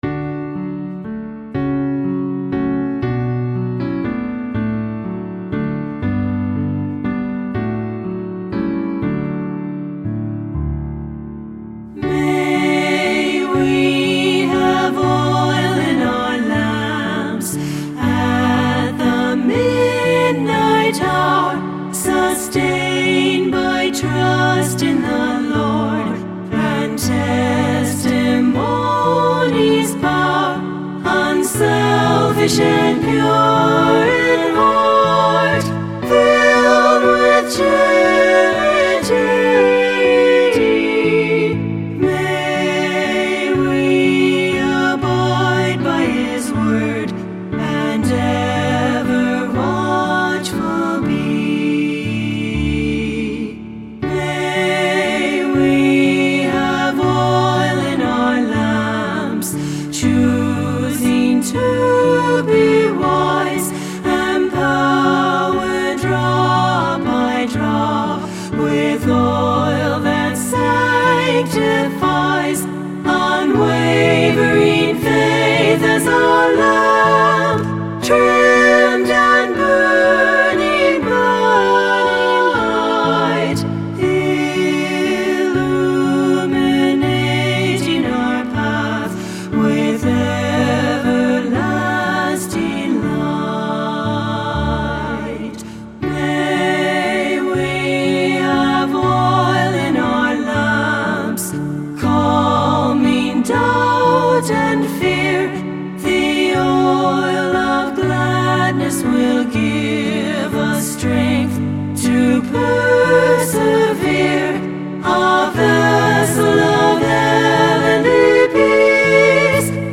SSA Trio
SSA Trio or Choir with Piano